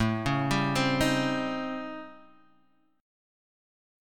A7#9b5 chord